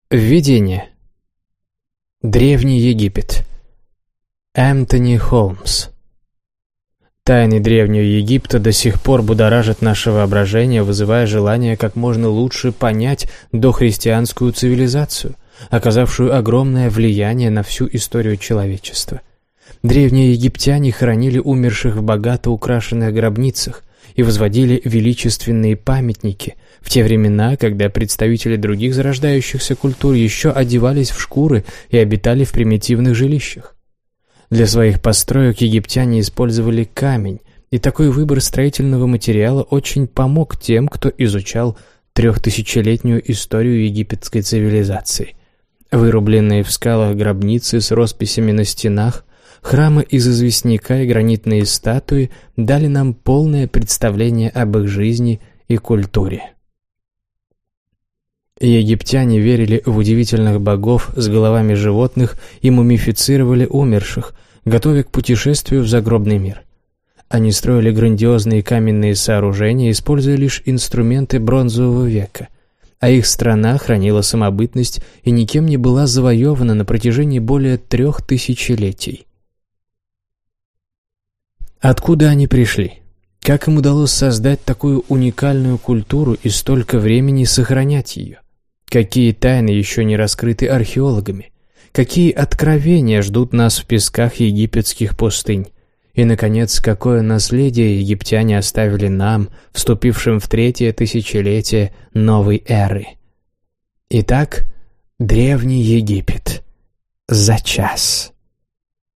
Аудиокнига Древний Египет | Библиотека аудиокниг